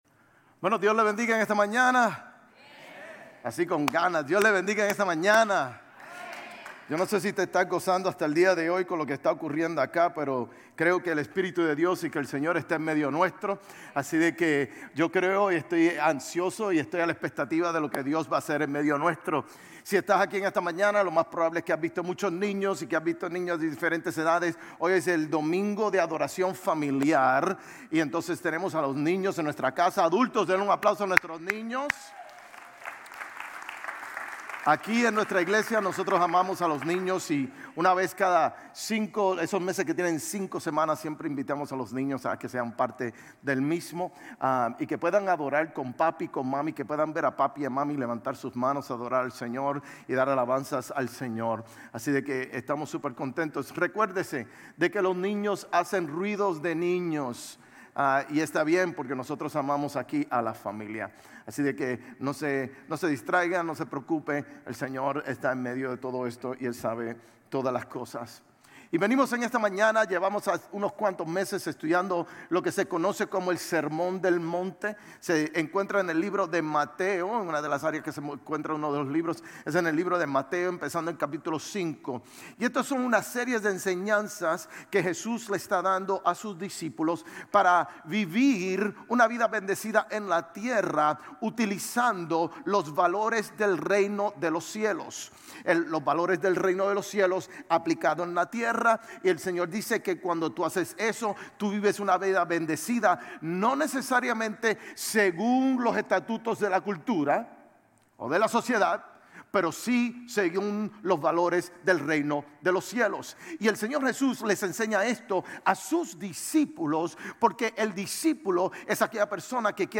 Sermones Grace Español 3_30 Grace Español Campus Mar 31 2025 | 00:39:15 1x 00:00 / 00:39:15 Subscribe Share RSS Feed Share Link Embed